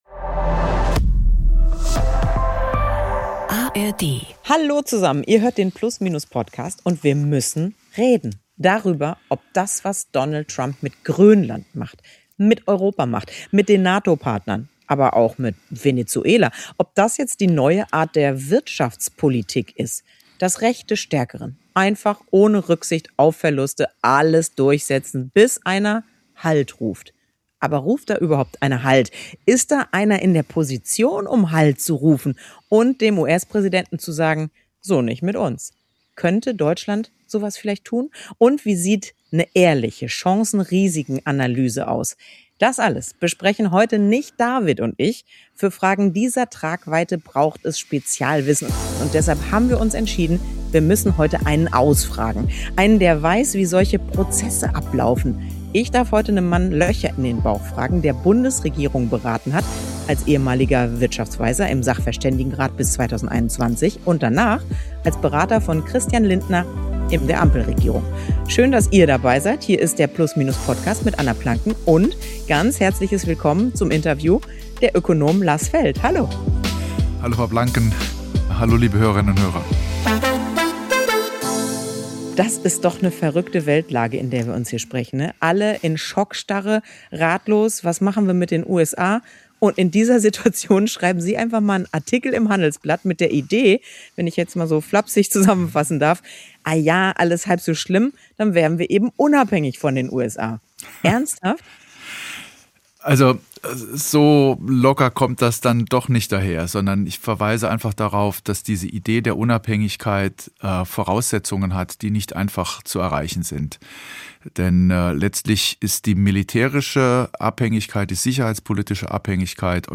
Zu Gast bei Plusminus Host Anna Planken war am 20.01.2026 der frühere Chef der Wirtschaftsweisen, Lars Feld. Er glaubt nicht, dass Europa als „Vasall“ der USA enden, aber schwere Entscheidungen treffen muss.